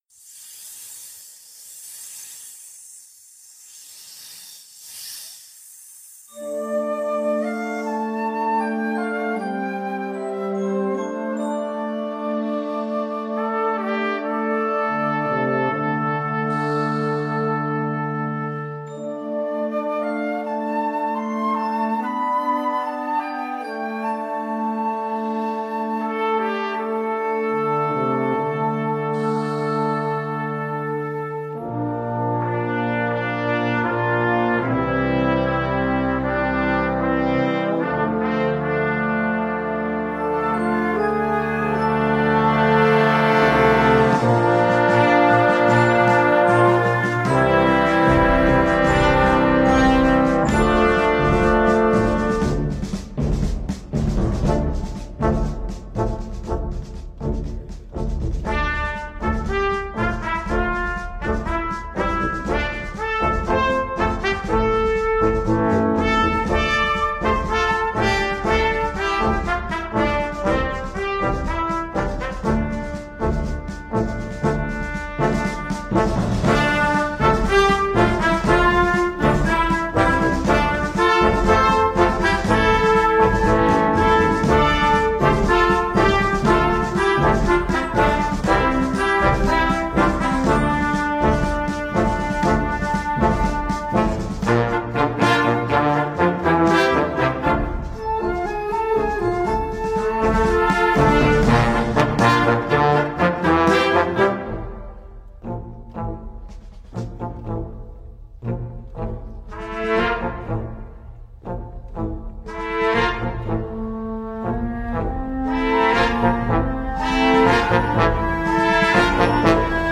Category Concert/wind/brass band
Instrumentation Ha (concert/wind band); YB (young band)